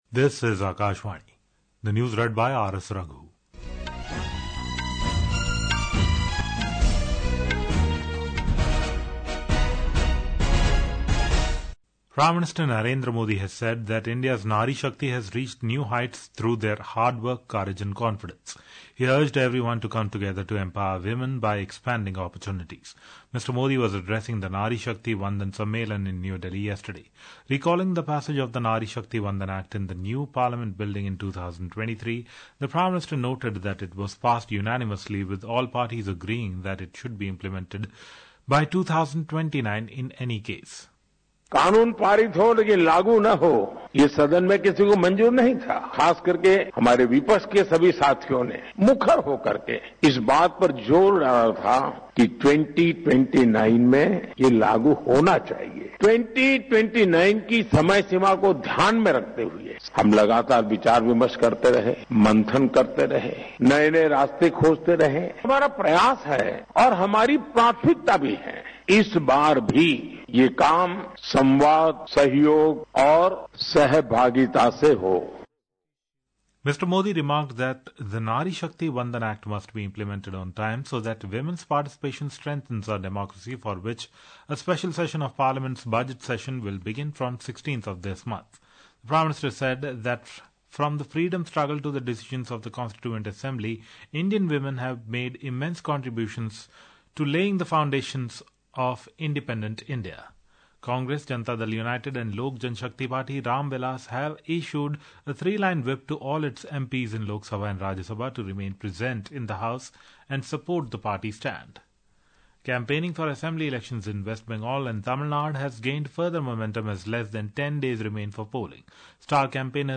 રાષ્ટ્રીય બુલેટિન
Hourly News